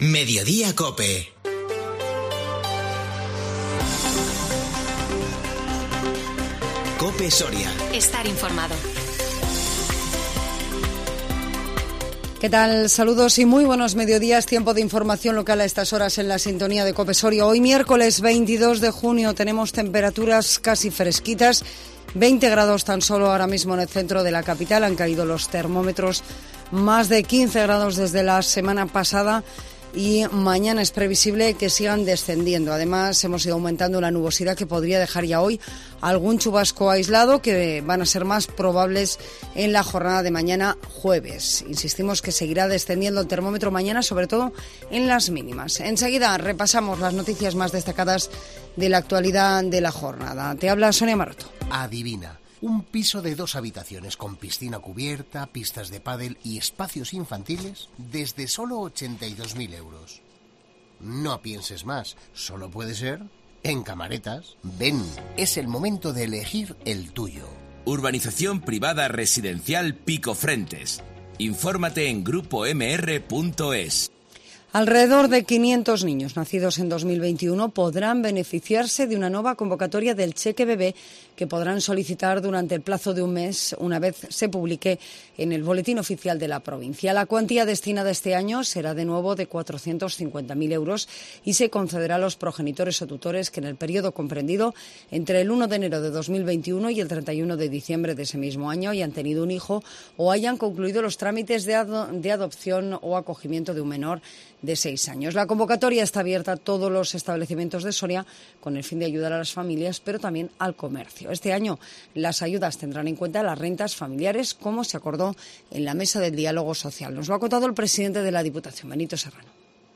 INFORMATIVO MEDIODÍA COPE SORIA 22 JUNIO 2022